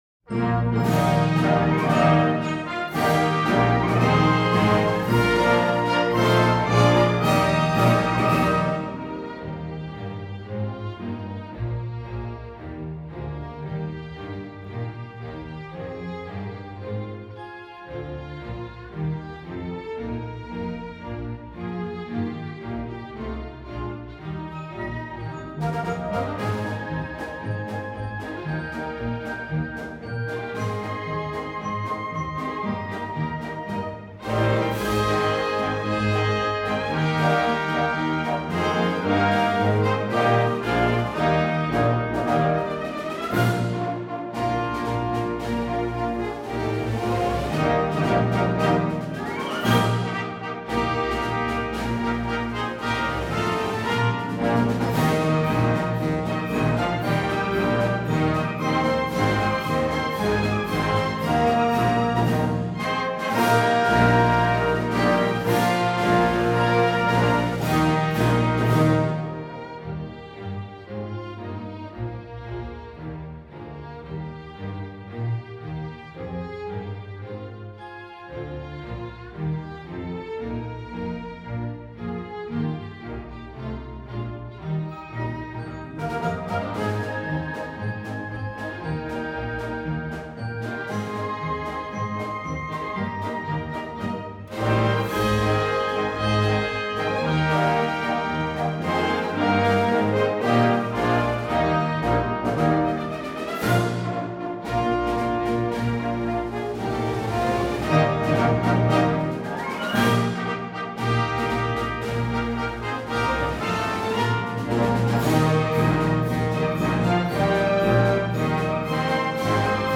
東京フィルハーモニー交響楽団による校歌
東京フィルハーモニー交響楽団による溝延小学校校歌をアップしました。